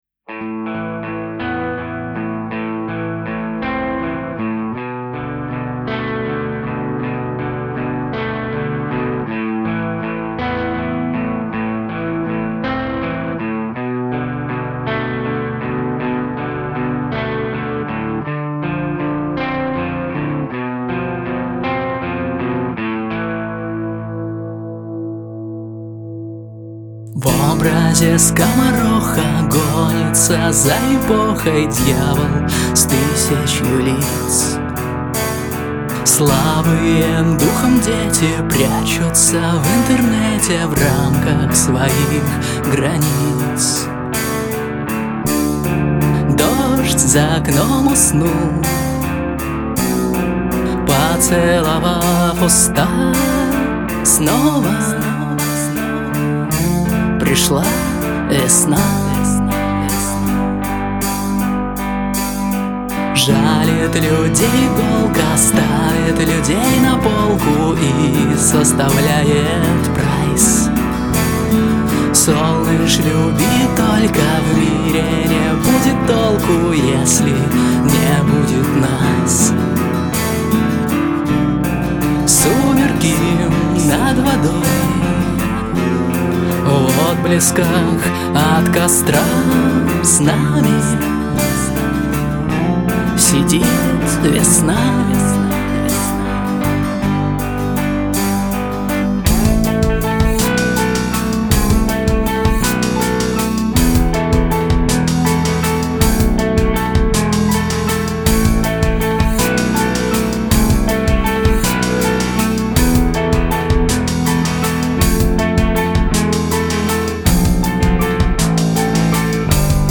У нас в гостях автор-исполнитель